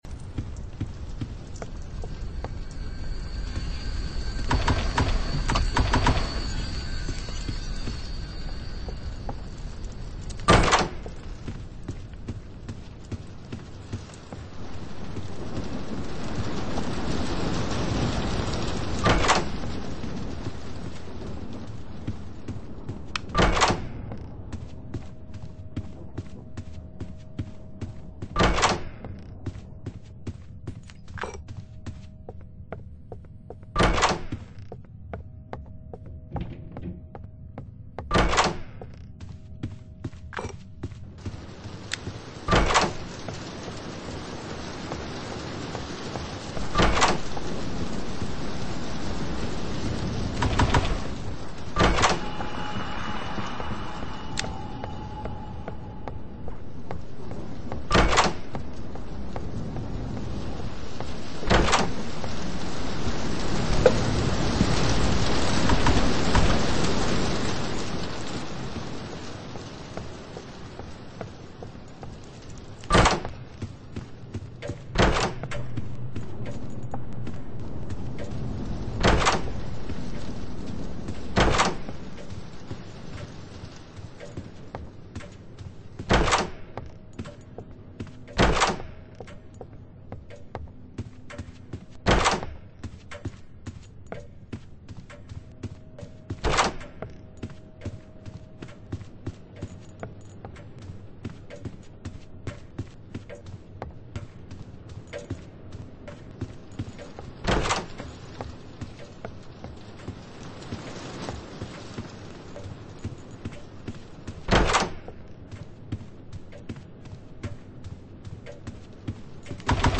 Roblox doors speedrun level 1-50 full gameplay with pc